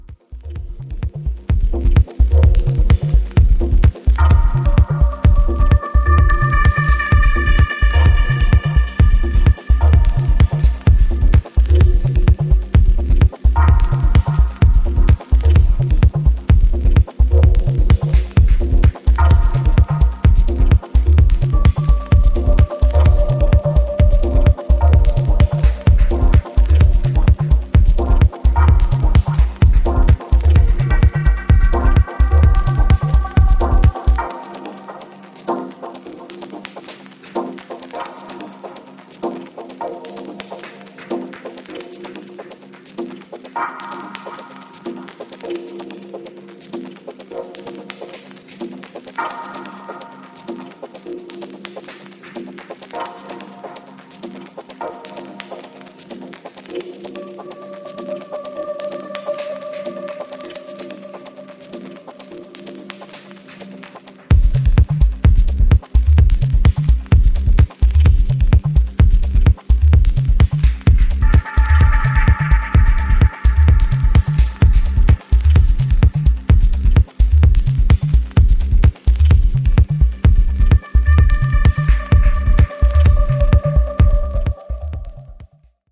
super stripped back and deep dubby house roller